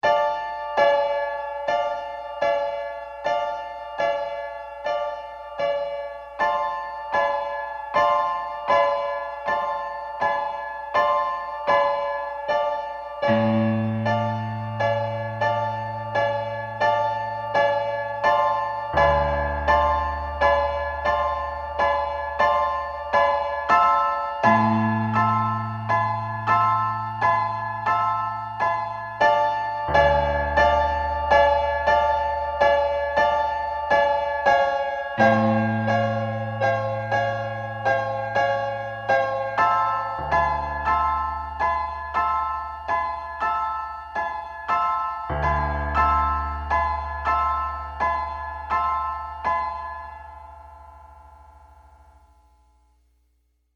PNOIMPRV1 (2.4 MB) - I decided to do a short piano improvisation in between each piece for this day.